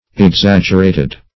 Exaggerated \Ex*ag"ger*a`ted\, a.